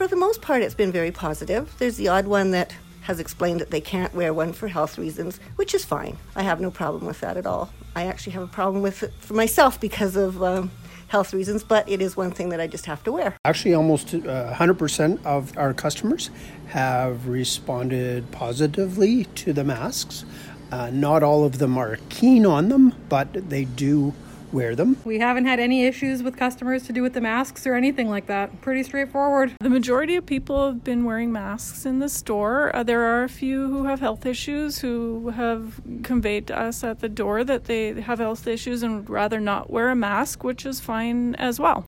CKDR spoke with several Dryden business owners to see how their customers were responding.